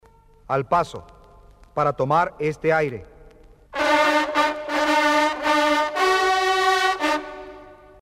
TOQUES MILITARES CON TROMPETA PARA EL ARMA DE CABALLERIA